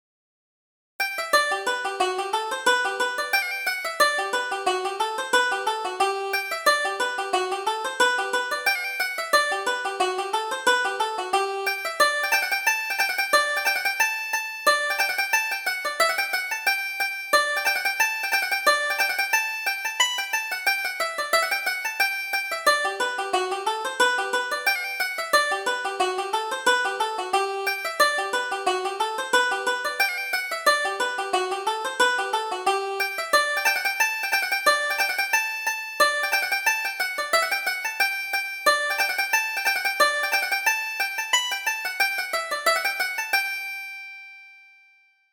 Reel: Captain O'Neill